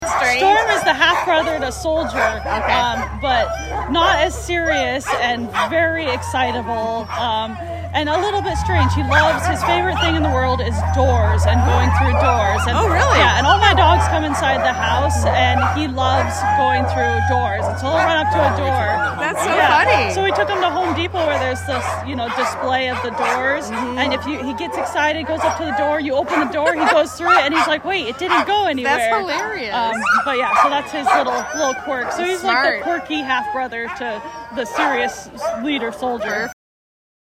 Current Location: Downtown Anchorage at the Ceremonial Iditarod Start